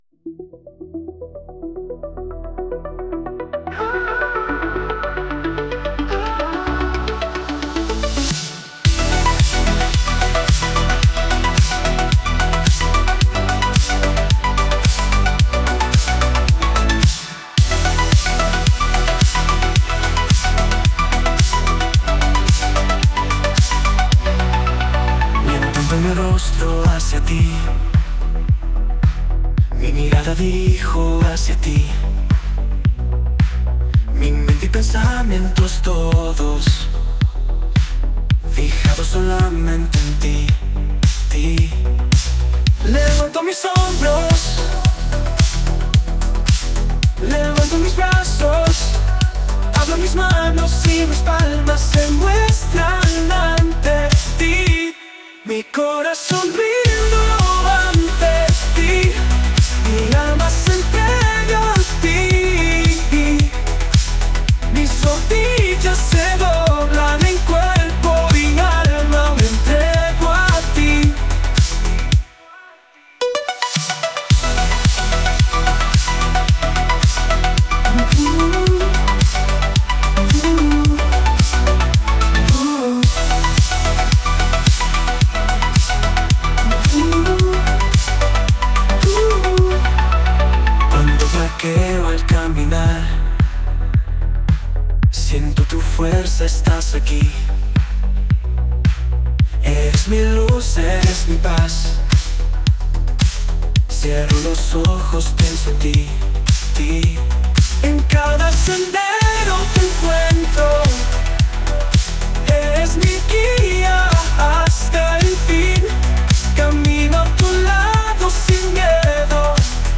género Synthwave